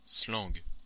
Ääntäminen
Ääntäminen France (Normandie): IPA: /slɑ̃ɡ/ Tuntematon aksentti: IPA: /slaŋ/ Haettu sana löytyi näillä lähdekielillä: ranska Kieli Käännökset englanti slang , English slang hollanti slang Suku: m .